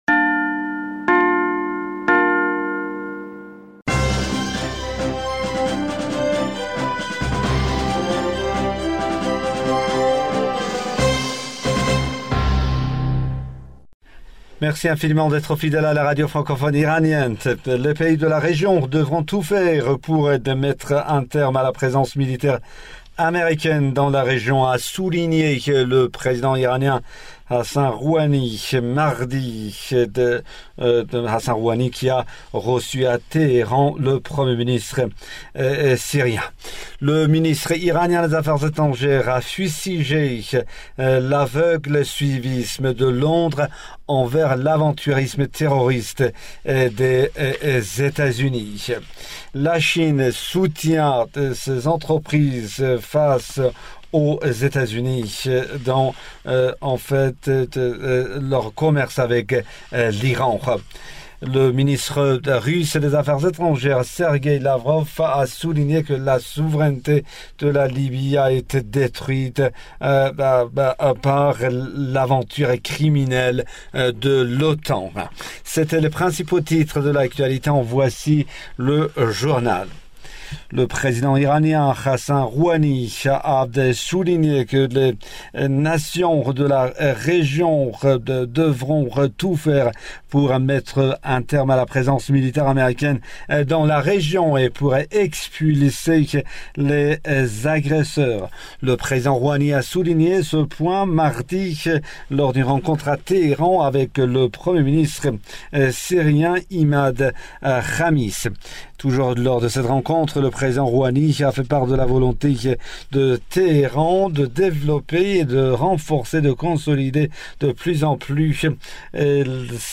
Bulletin d'information du 14 janvier 2020